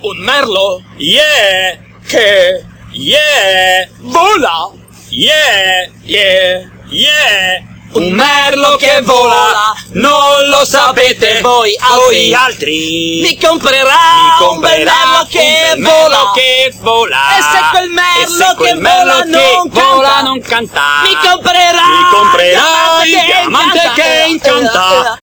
Un merlo... LOUD
Category: Comedians   Right: Personal